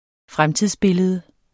Udtale [ ˈfʁamtiðs- ]